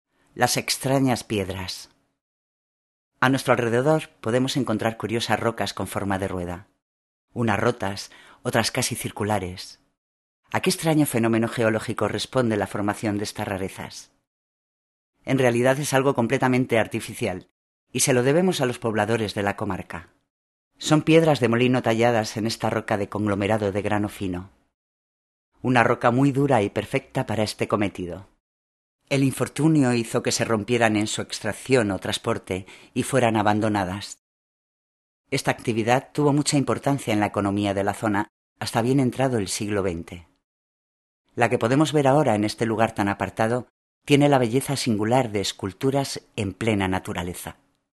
Locucion: